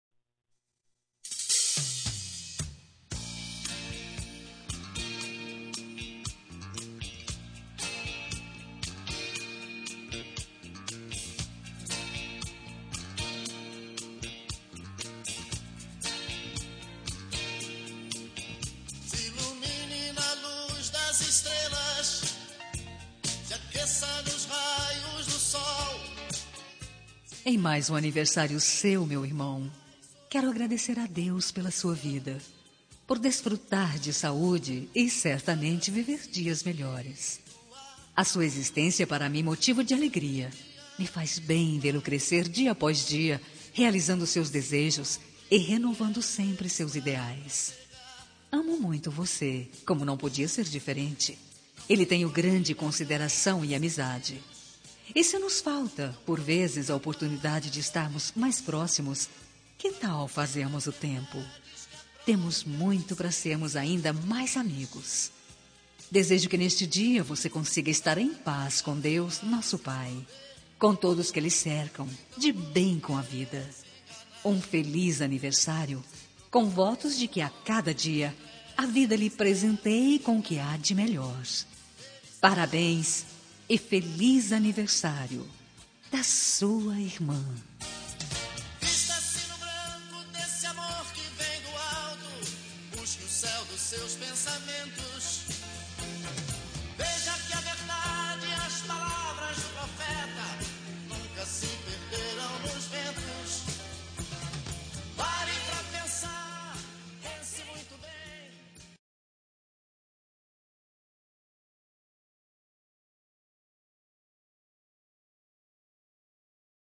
Telemensagem de Aniversário de Irmão – Voz Feminina – Cód: 1703 – Religiosa